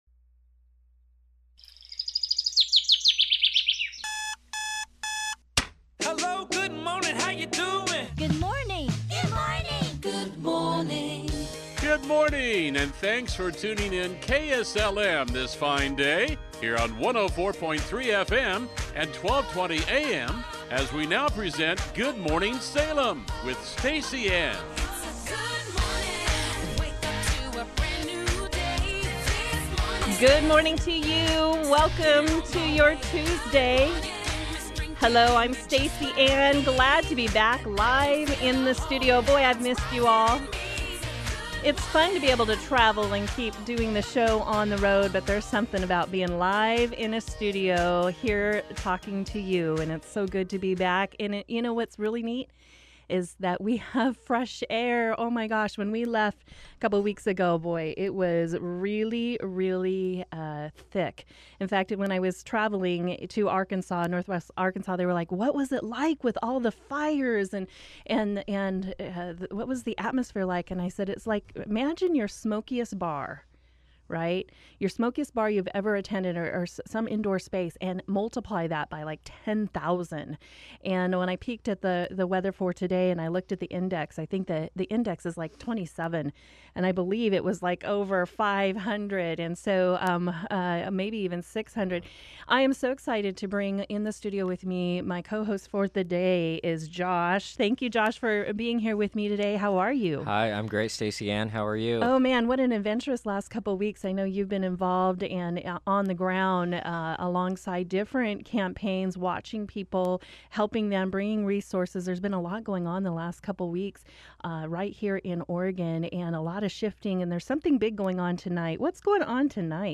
We together interview Danielle Bethell, Executive Director of the Keizer Chamber, Vice-Chair of the Salem-Keizer school board and candidate for the Marion County Commission.